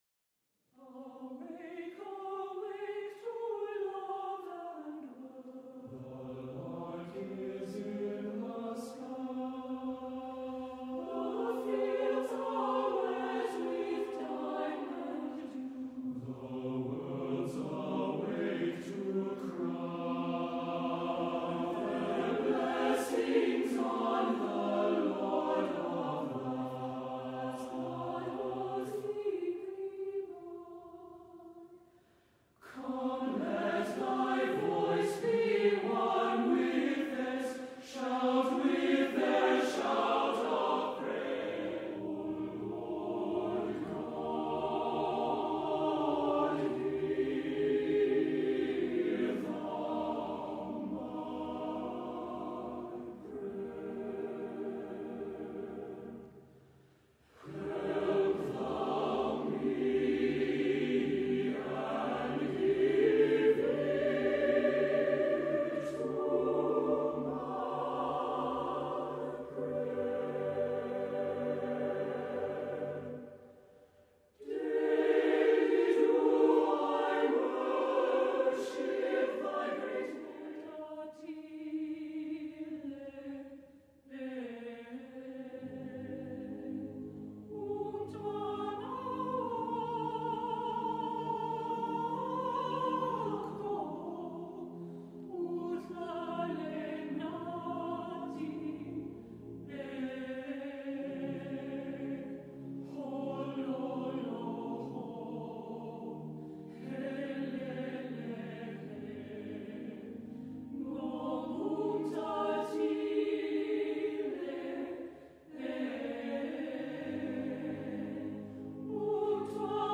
All songs acappella.